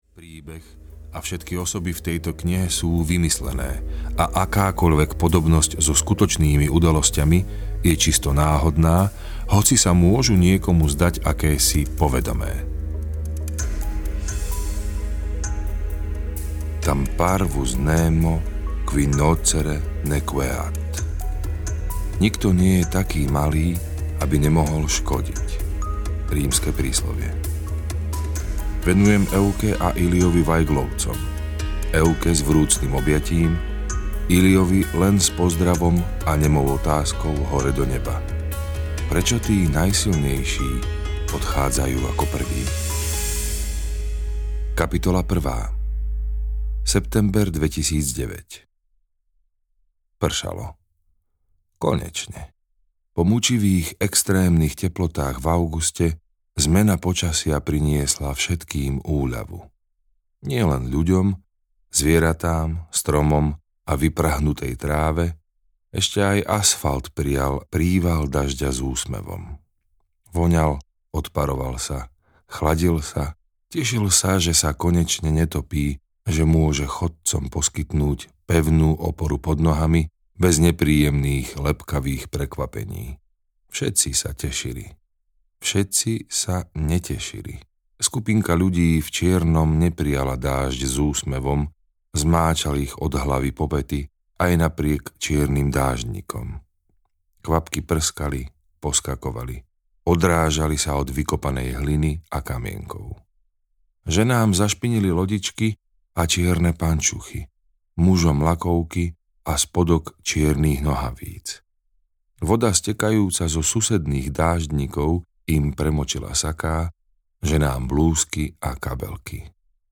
Pochovaní zaživa audiokniha
Ukázka z knihy